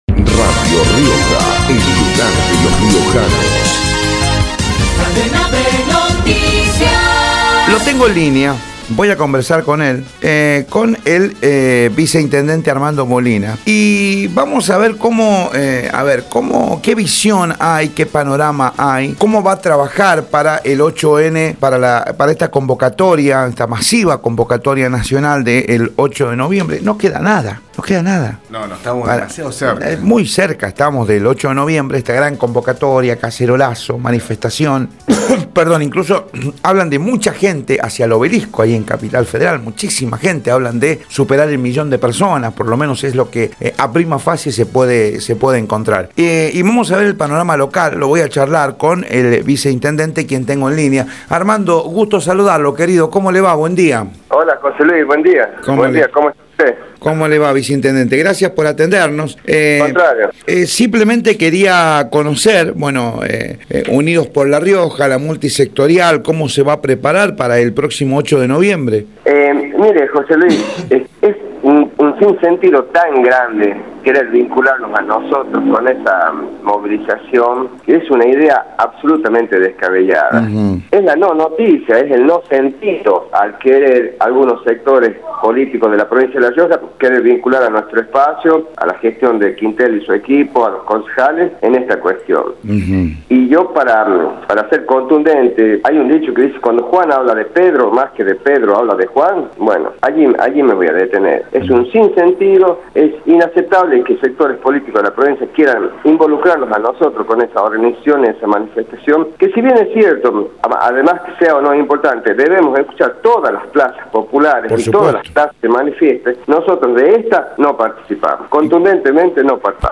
Armando Molina, viceintendente, en Radio Rioja
armando-molina-viceintendente-por-radio-rioja.mp3